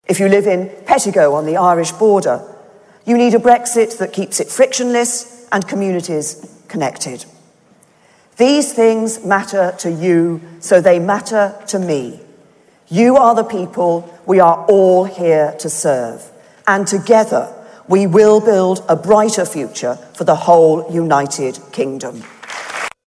It comes after British Prime Minister Theresa May gave a fiery speech to her Tory party at the end of their annual conference.
In her speech the Prime Minister said for those living along the border in Donegal a frictionless Brexit is necessary: